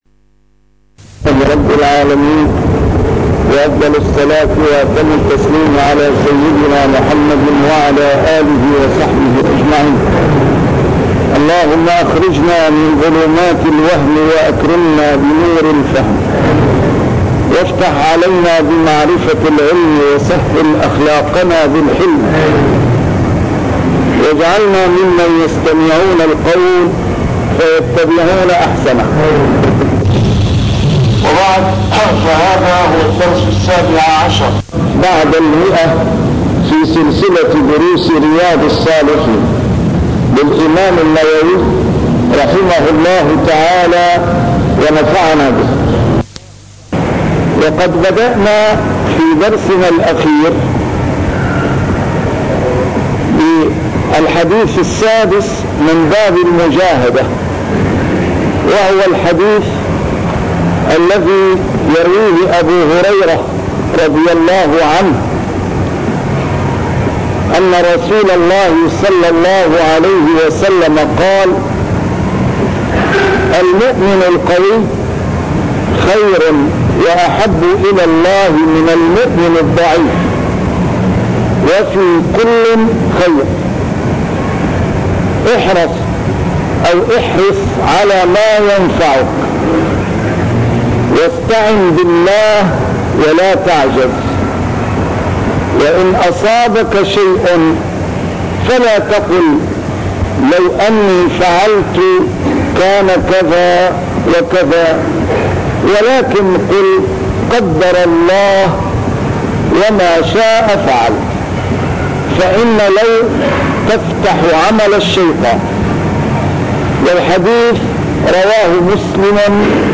A MARTYR SCHOLAR: IMAM MUHAMMAD SAEED RAMADAN AL-BOUTI - الدروس العلمية - شرح كتاب رياض الصالحين - 117- شرح رياض الصالحين: المجاهدة